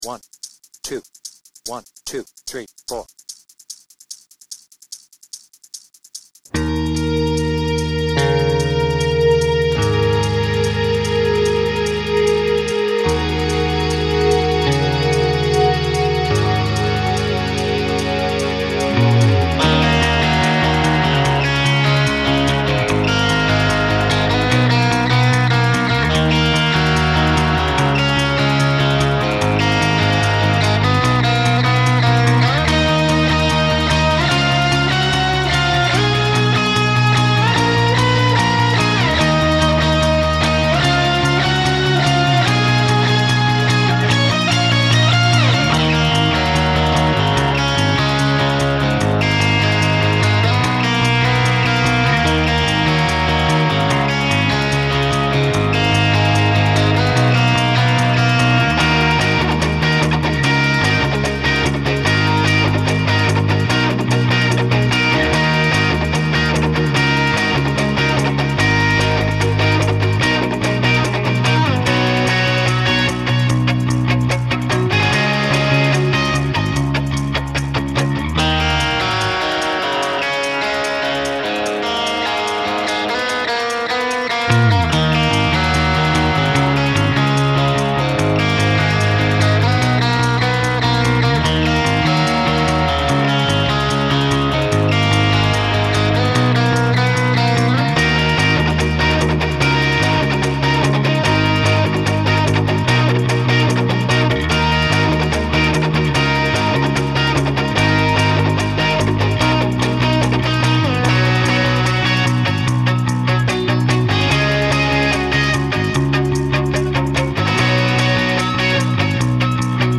BPM : 147
Tuning : Eb
Without vocals
live version